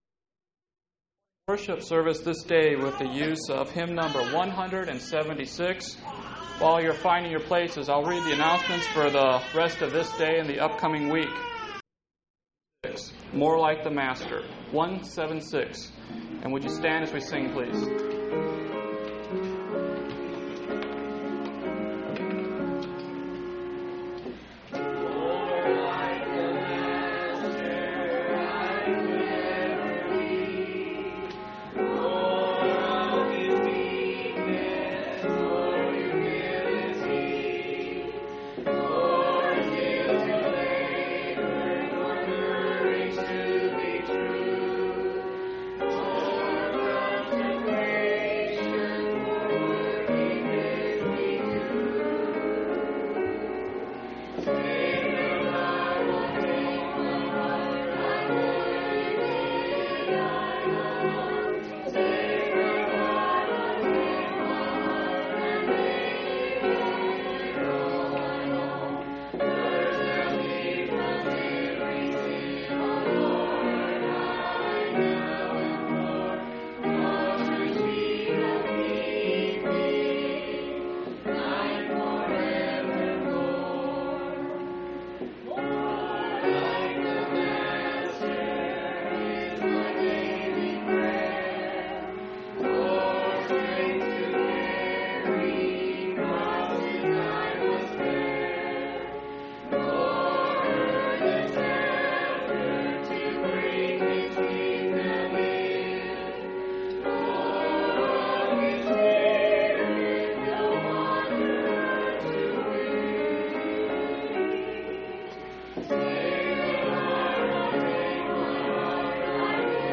9/27/1992 Location: Phoenix Local Event